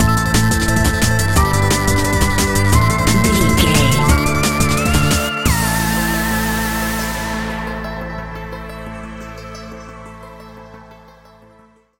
Aeolian/Minor
Fast
aggressive
dark
driving
energetic
groovy
drum machine
synthesiser
break beat
sub bass
synth leads